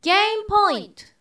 ui_scoremgs_gamepoint.wav